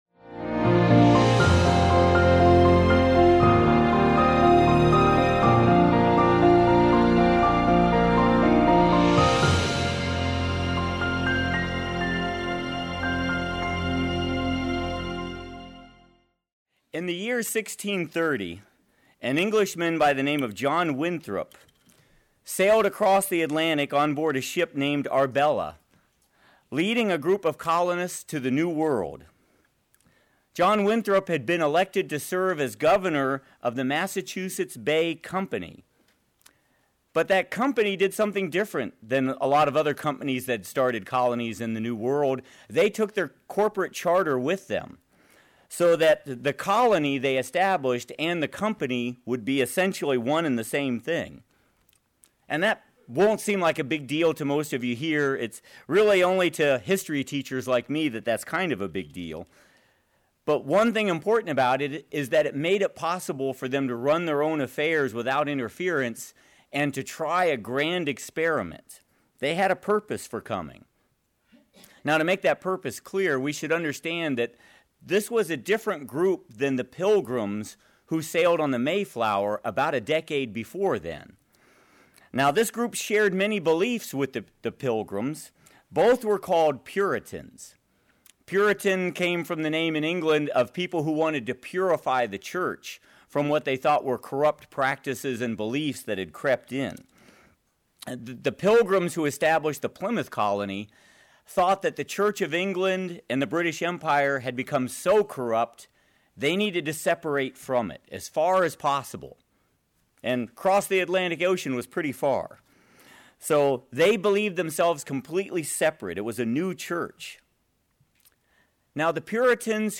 As Christians we are to be the "light of the world," but what does that mean, and how do we do it? This message will look at ways we can be light in a dark world.